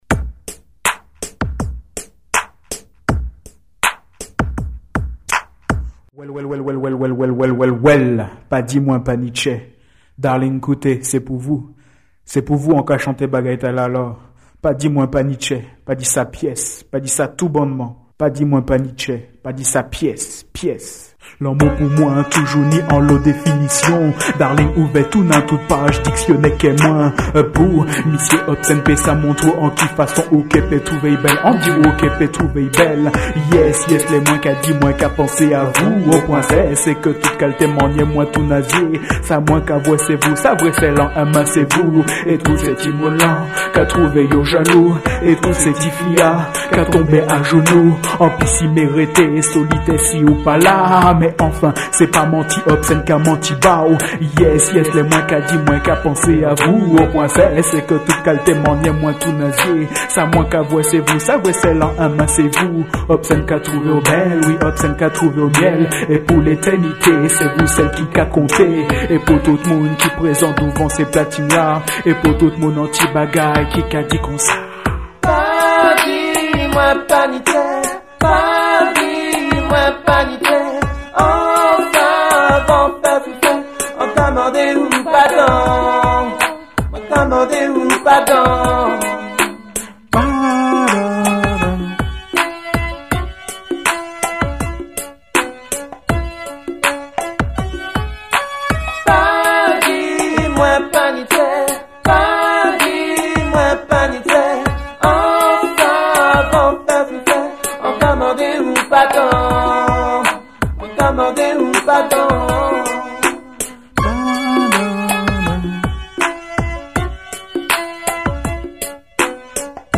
choeurs